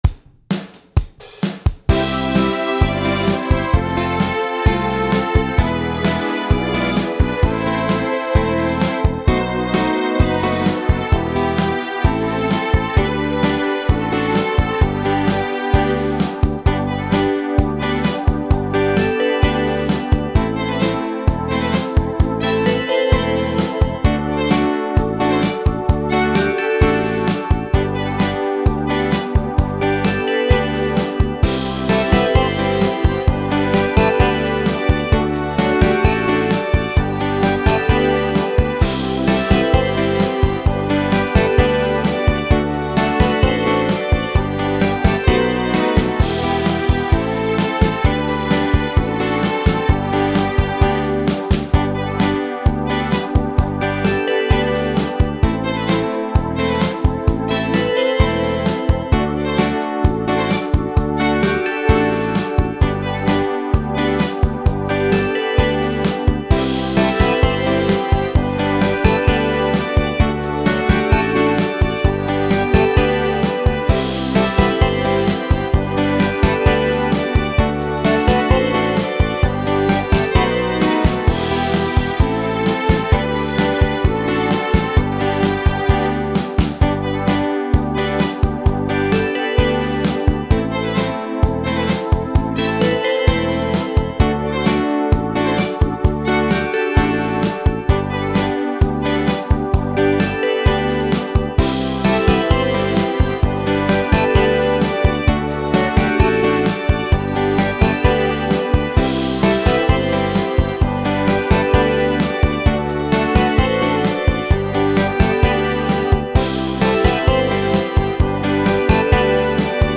• Жанр: Детские песни
Слушать Минус